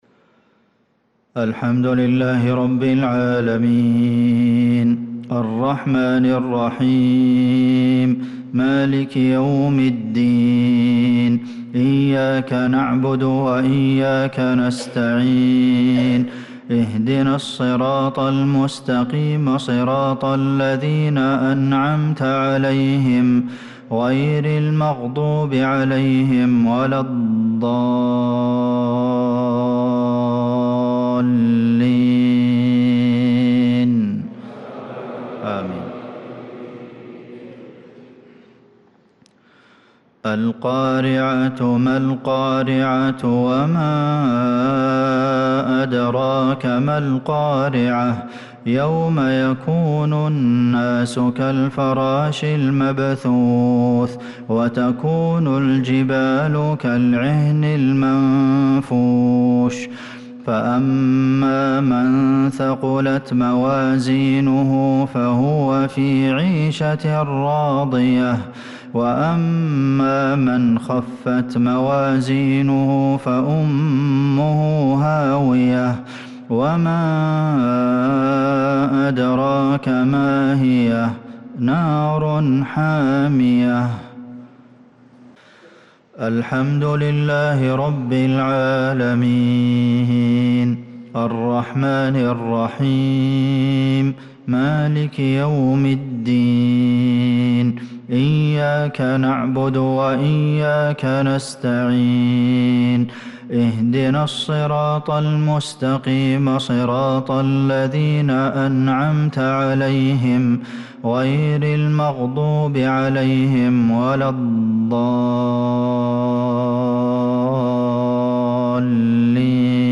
صلاة المغرب للقارئ عبدالمحسن القاسم 17 ربيع الآخر 1446 هـ
تِلَاوَات الْحَرَمَيْن .